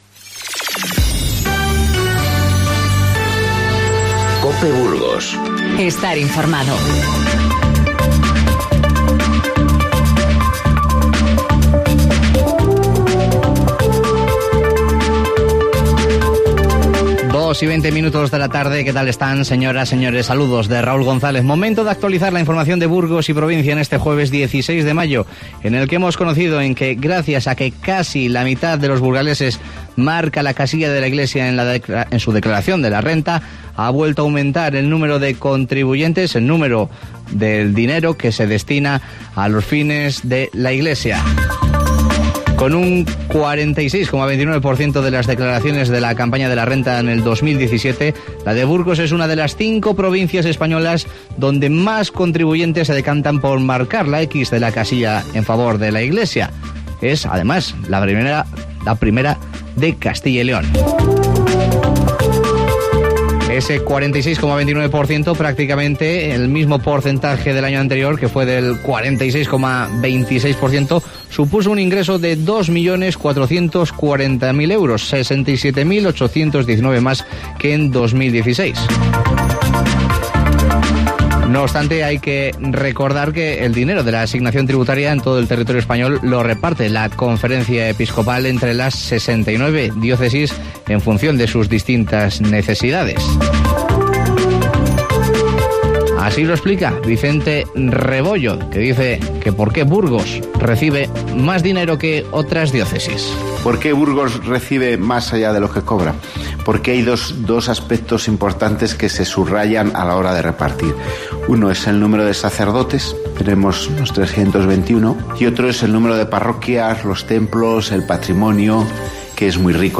Informativo Mediodía COPE Burgos 16/05/19